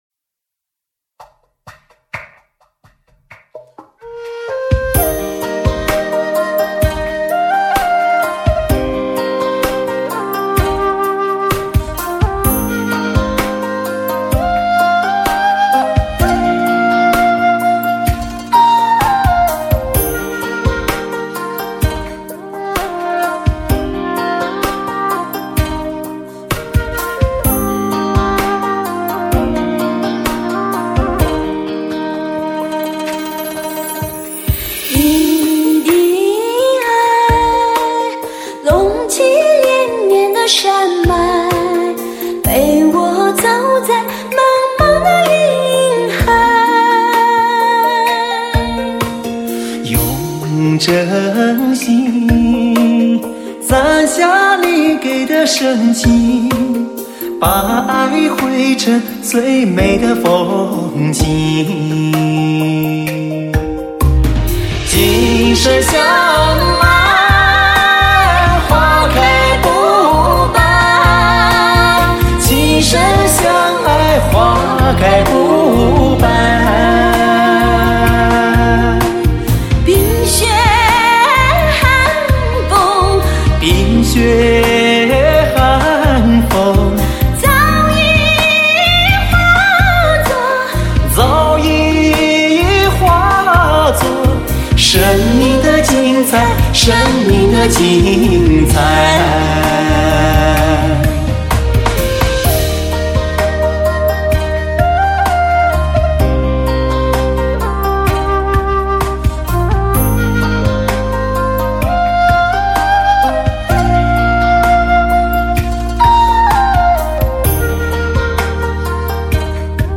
专辑格式：DTS-CD-5.1声道